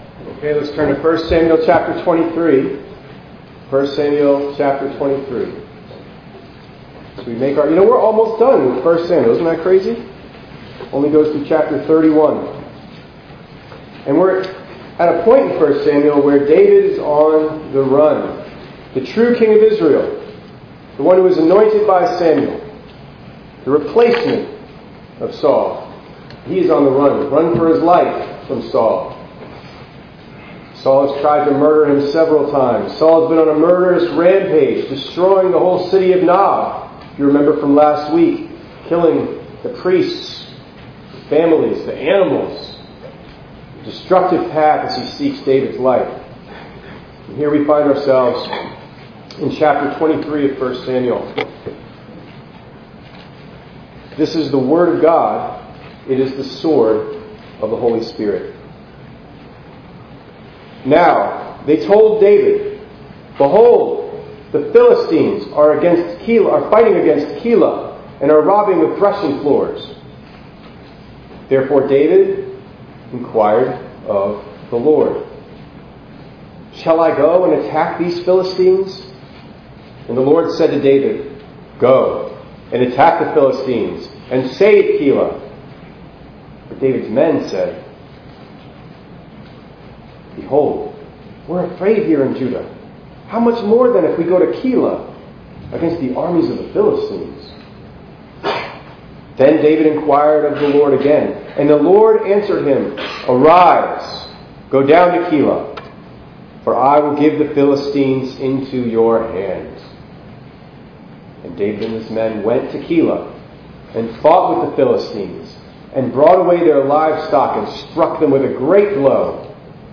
10_5_25_ENG_Sermon.mp3